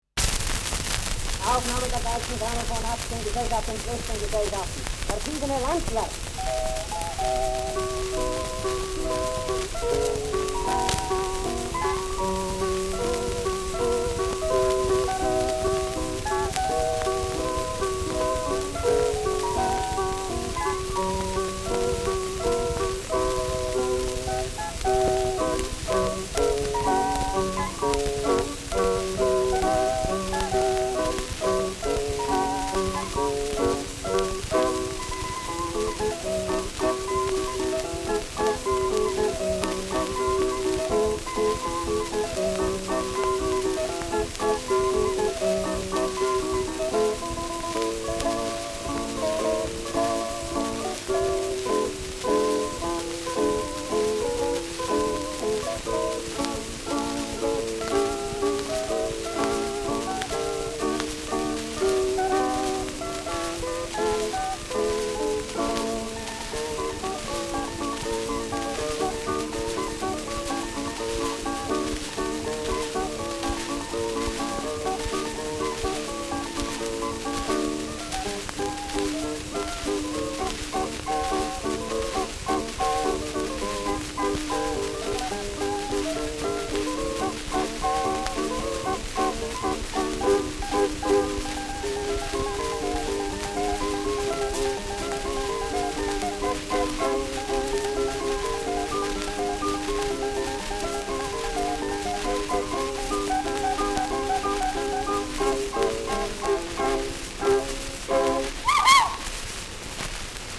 Schellackplatte
präsentes Rauschen : abgespielt : Knistern : leichtes Leiern : Nadelgeräusch
Ländlerfolge ohne Zwischenspiele. Juchzer am Ende.